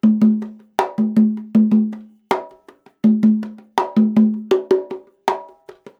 80 CONGA 3.wav